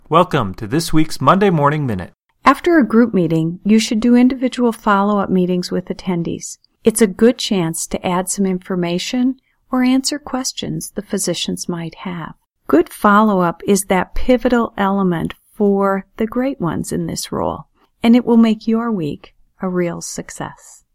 Welcome to B/Mc’s Monday Morning Minute, an audio message to jump start your week. The brief messages include reminders, new ideas and sometimes a little therapy to slide into the work week with ease.